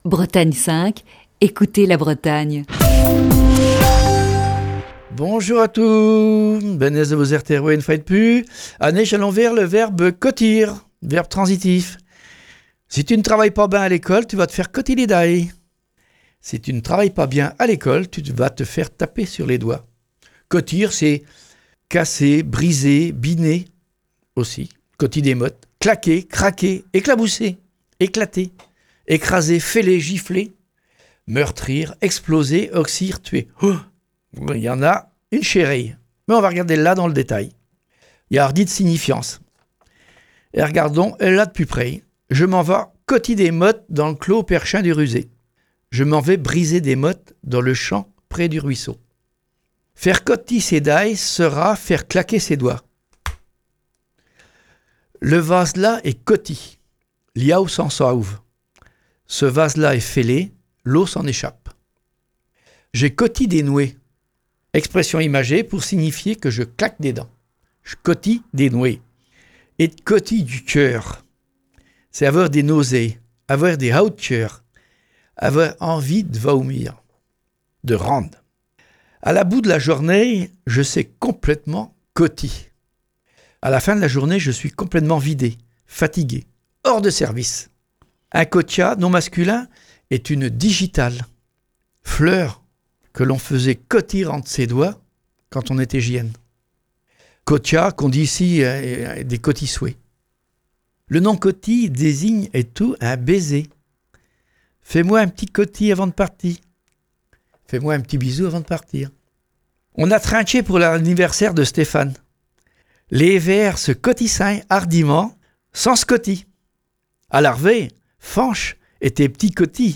Chronique du 12 août 2020. Pause estivale pour Le mot à kneute.